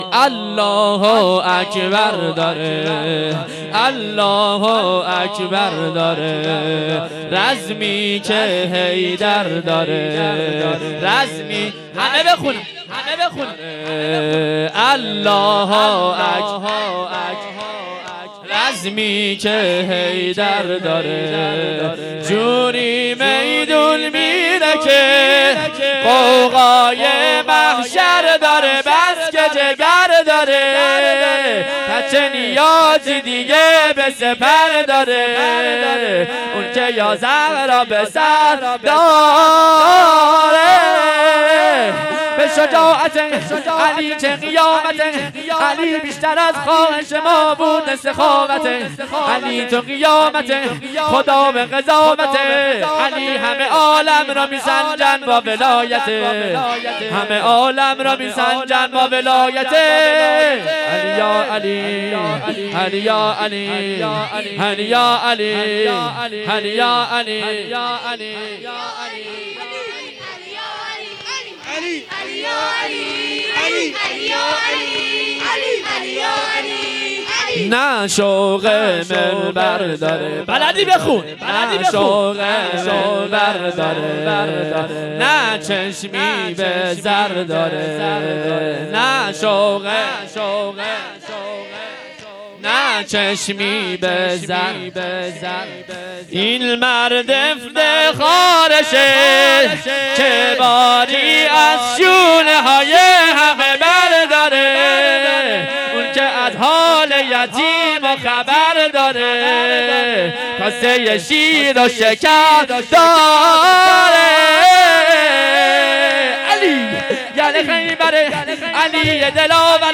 شور
محرم 1442 شب چهارم